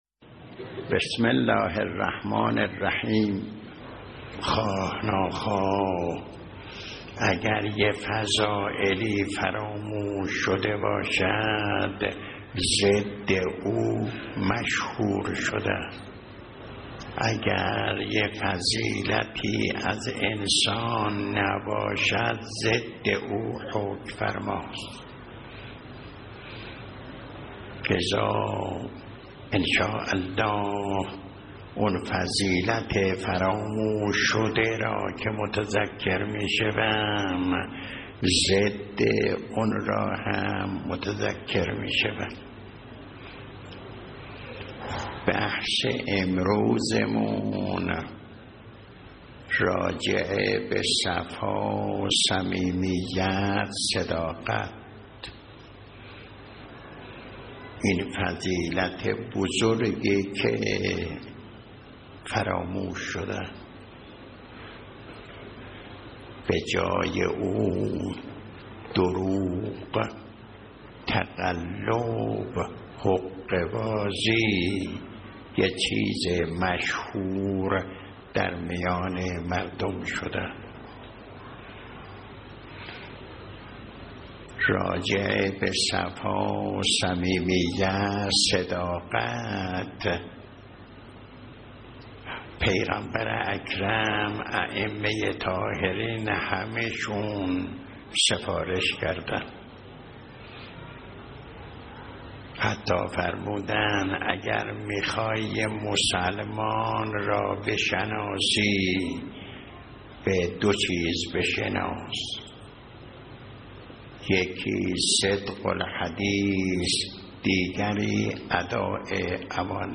درس اخلاق | راز ماندگاری ازدواج‌های قدیمی چه بود؟